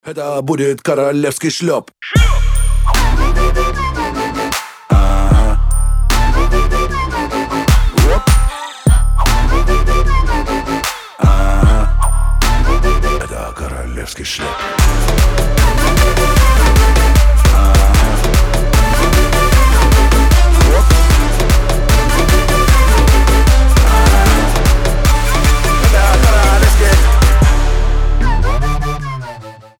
• Качество: 320, Stereo
веселые
Trap
басы
качающие
Moombahton